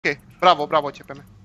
descargar sonido mp3 bravo bravo
bravo-bravo.mp3